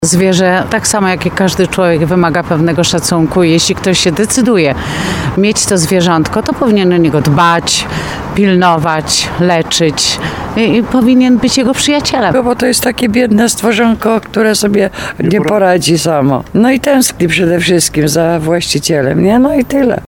Mieszkańcy Tarnowa, z którymi rozmawialiśmy przyznają, że problem jest zauważalny. Najczęściej jest to skutek pochopnych decyzji.